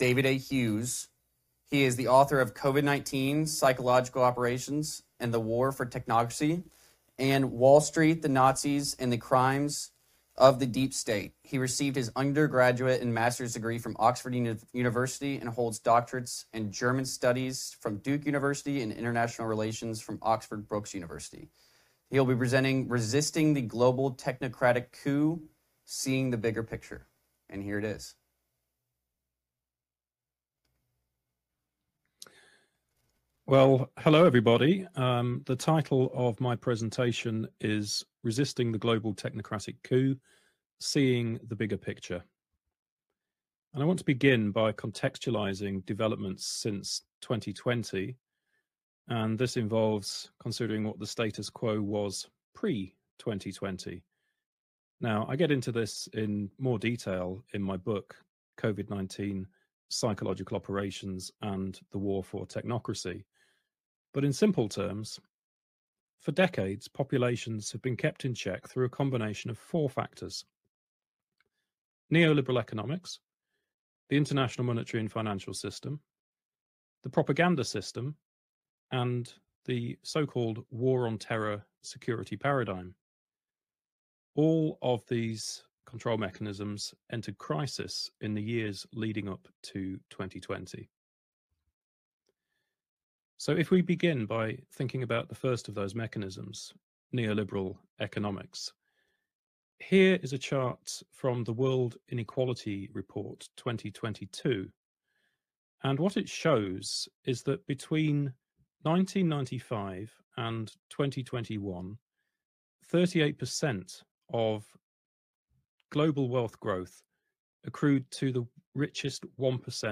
Entire video - Omniwar Symposium - Sept. 21, 2024 by Children's Health Defense